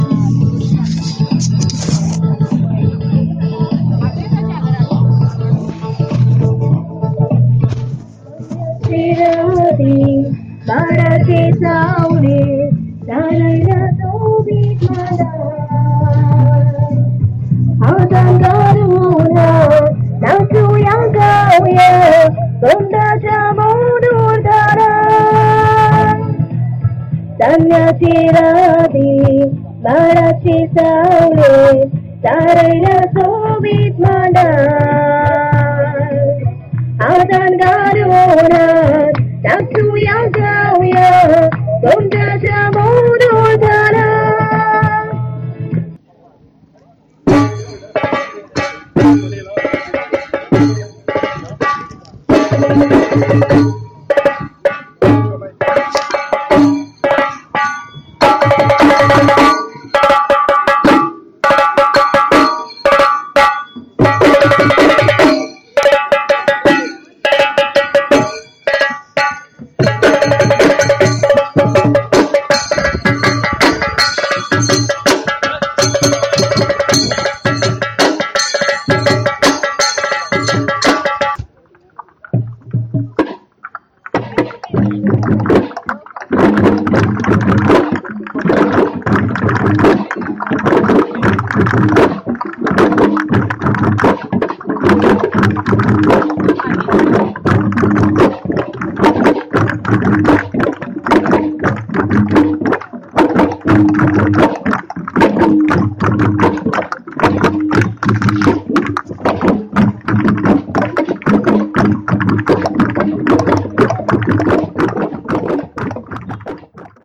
Madkyanche Fest – a celebration of traditional instruments made from earthen pots
[highlight]Songs and music at Madkyanche Fest[/highlight]
It was a lovely little festival where locals and visitors took part in singing and dancing to the music. The festival highlights the traditional purcussion instruments made from earthen pots covered with goat leather.